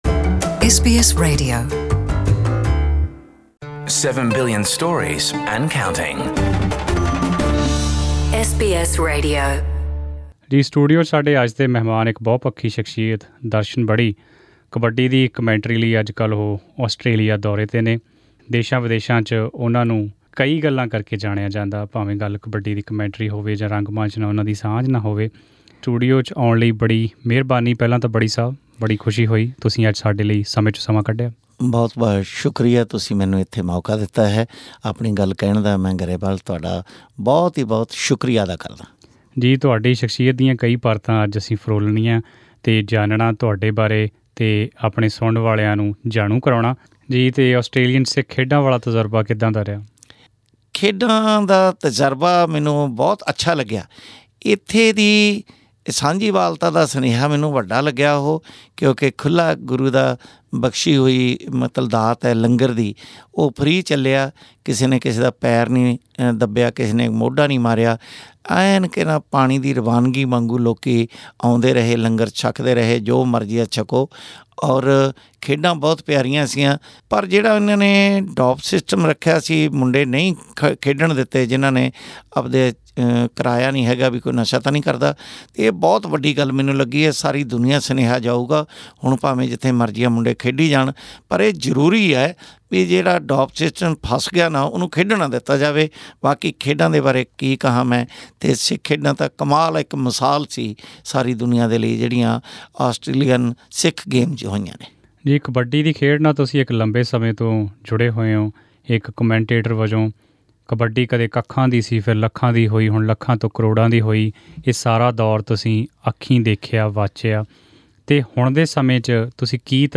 at SBS Studio, Melbourne